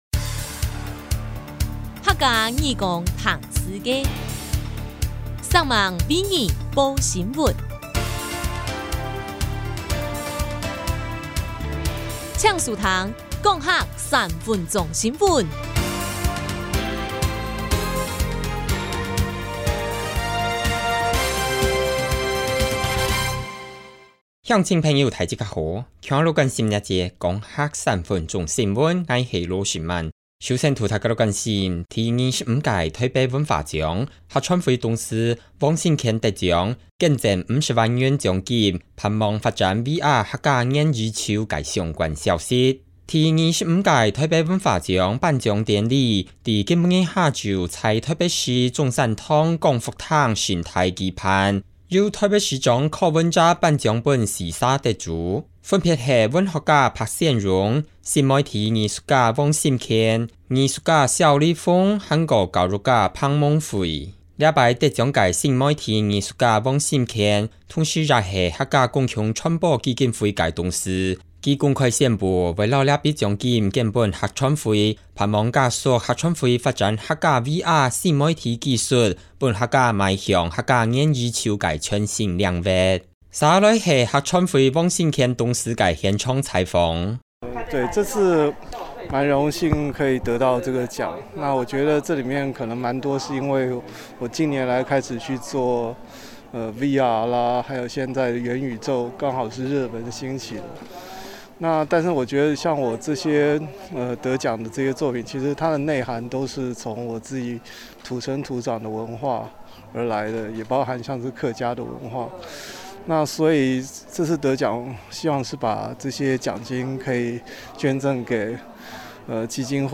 第25屆臺北文化獎頒獎典禮即時新聞